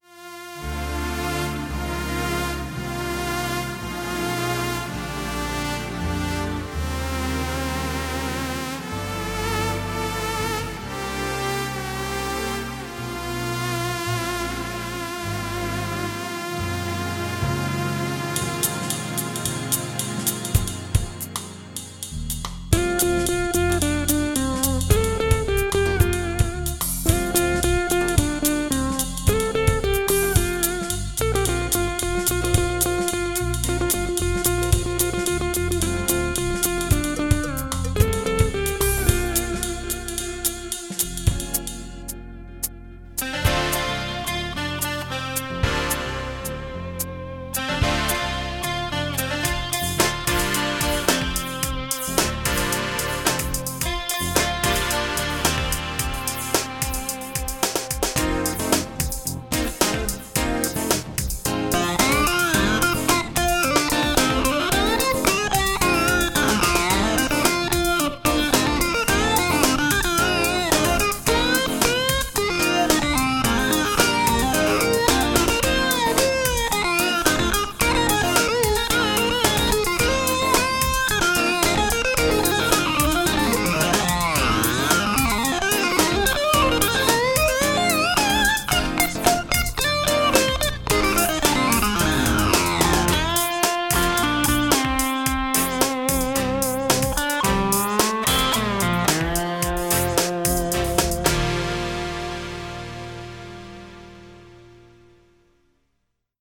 *-2-* OldMcDonald had a farm ... and a fretless guitar !
All melodies and lead parts played on a
fretless guitar with metal fingerboard,
commercial stompboxes, a slide and an E-bow (intro).
00:58 - 01:33 during which you are hearing the sounds of just 2 guitars and drums.